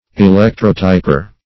Electrotyper \E*lec"tro*ty`per\, n.